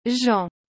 A pronúncia correta é “Jã”, com aquele som nasal que lembra um pouco “Jão”, mas sem o “o” no final.
• Jean → “Jã”